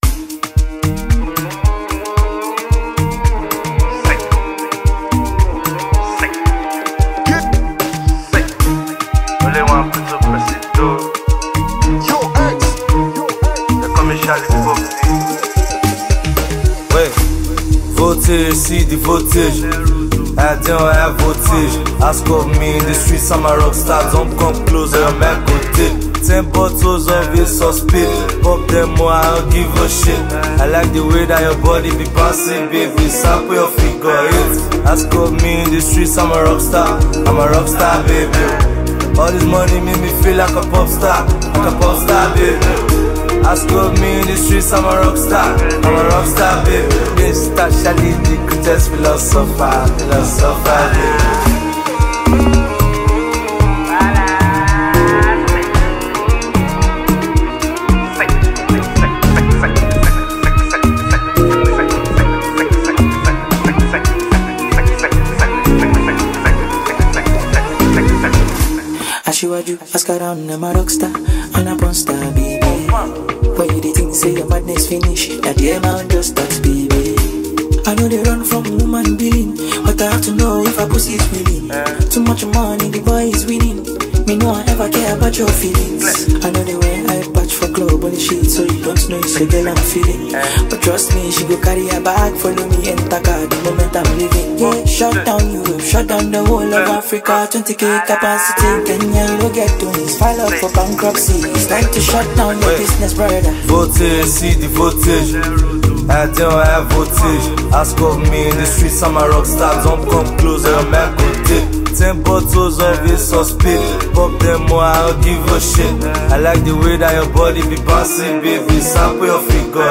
a smooth, energetic vibe